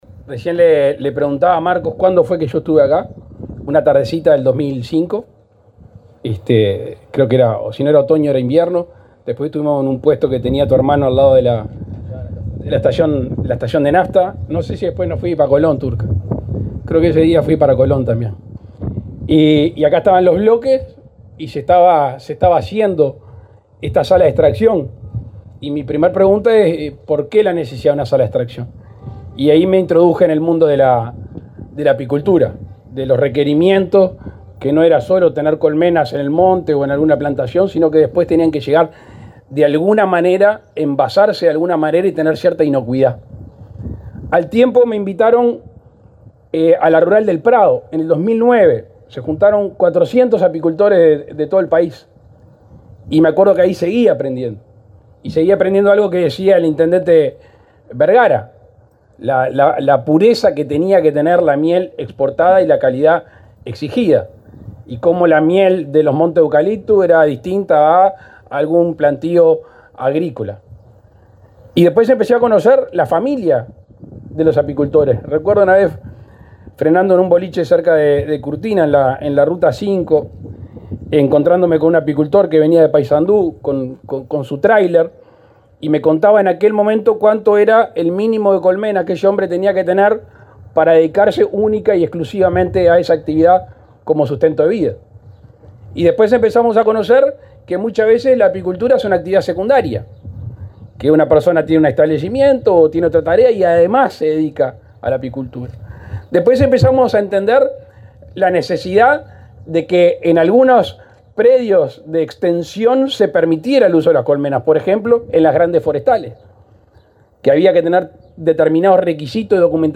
Palabras del presidente Luis Lacalle Pou
El presidente de la República, Luis Lacalle Pou, asistió este viernes 4 a la inauguración de la zafra apícola, en la localidad de José Pedro Varela,